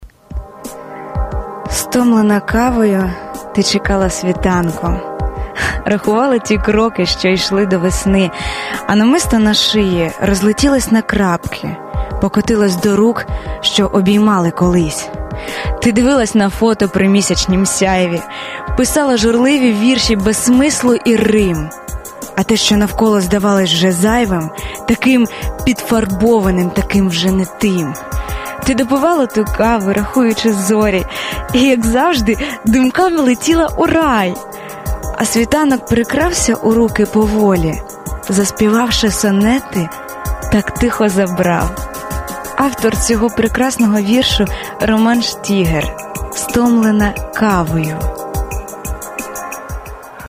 стомлена кавою (Яскраве радіо. 05. 02. 11р)
Рубрика: Поезія, Лірика